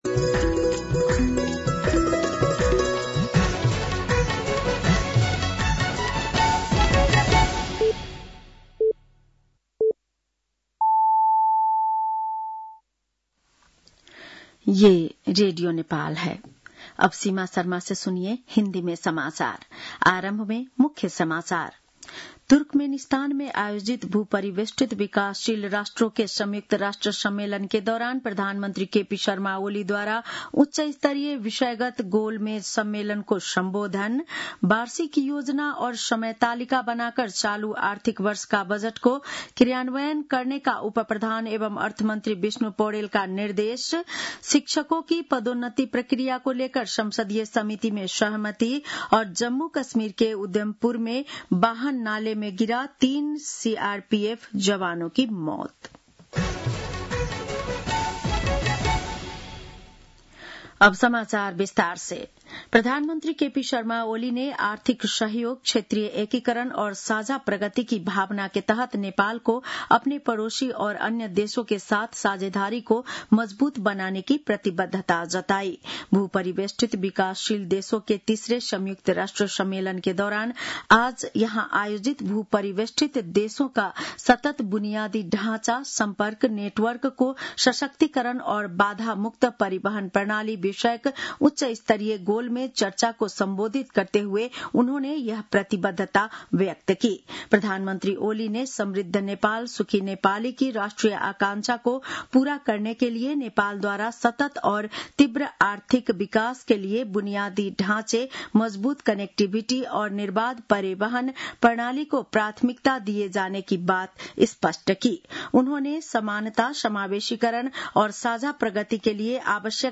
बेलुकी १० बजेको हिन्दी समाचार : २२ साउन , २०८२